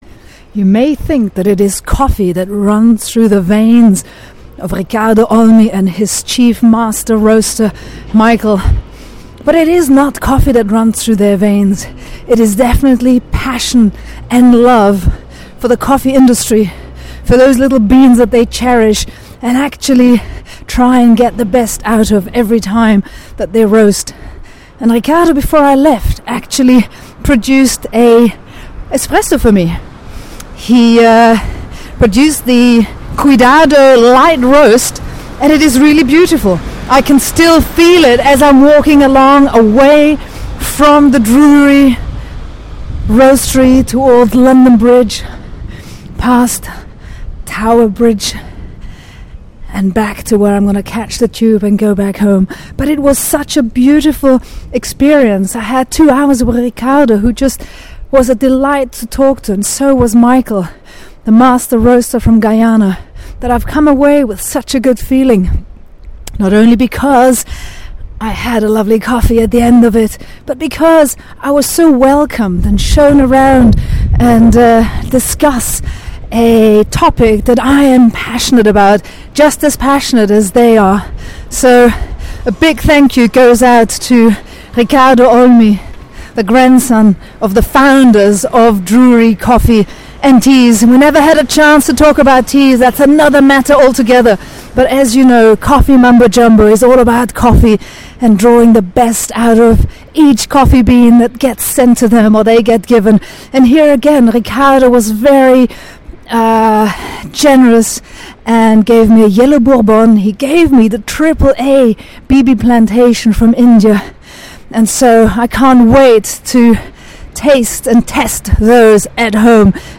Walking back to London Bridge Tube station, past the Shard, reflecting on my amazing time spent at the Drury Roastery.